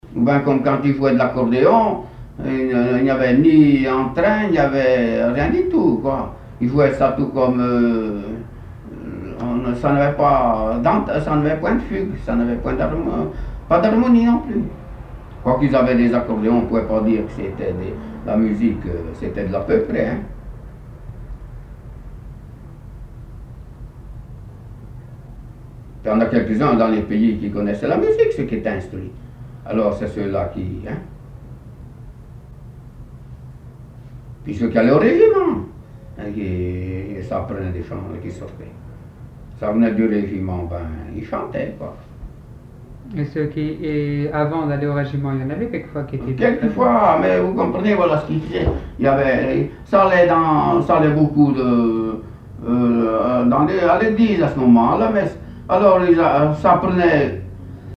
chansons et témoignages parlés
Catégorie Témoignage